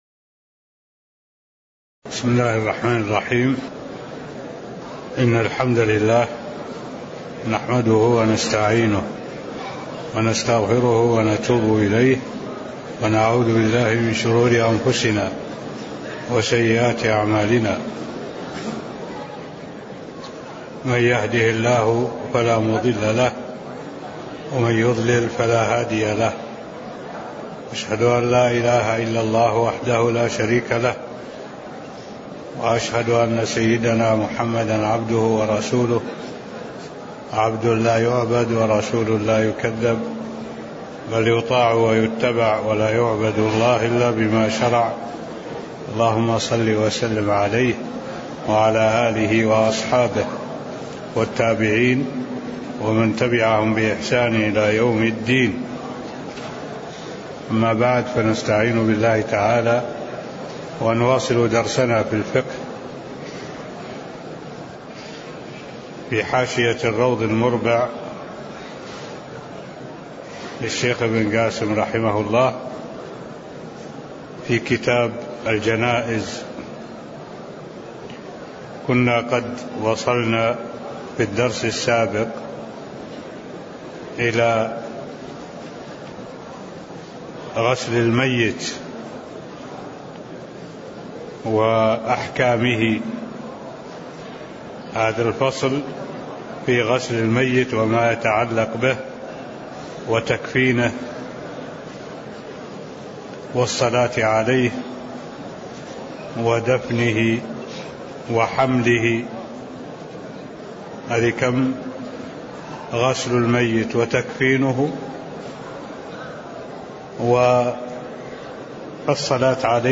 تاريخ النشر ١١ محرم ١٤٢٩ هـ المكان: المسجد النبوي الشيخ: معالي الشيخ الدكتور صالح بن عبد الله العبود معالي الشيخ الدكتور صالح بن عبد الله العبود غسل الميت ومايتعلق به (007)